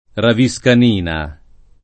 [ ravi S kan & na ]